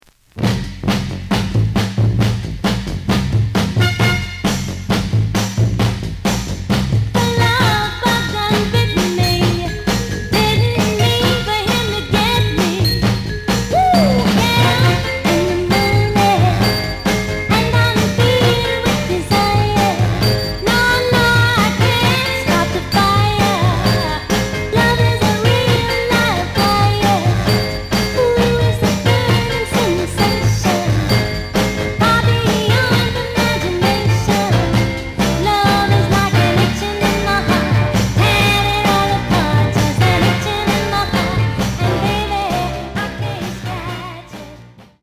The audio sample is recorded from the actual item.
●Genre: Soul, 60's Soul
Some click noise on fist half of A side due to scratches.